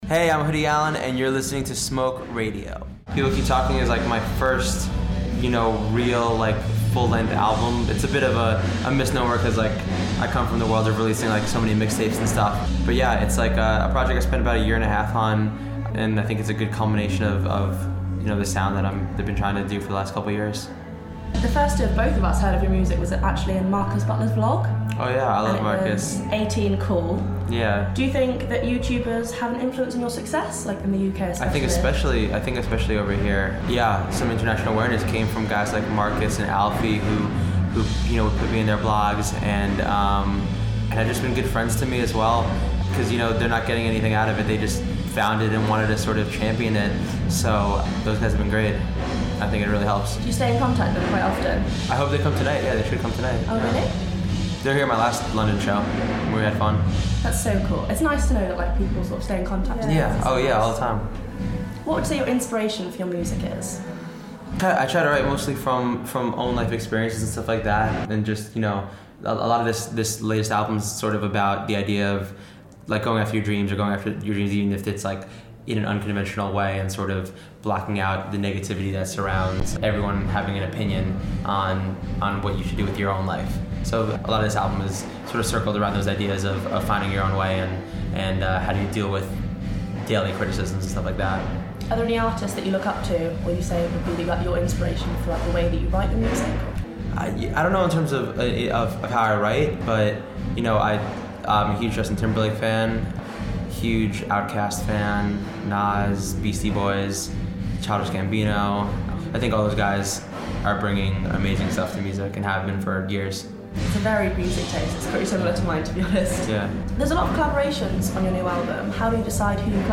down to the Electric, Brixton to have a chat with Hoodie Allen ahead of his gig. Hoodie speaks about fans, gigs, favourite song to perform but he starts by talking about his latest album.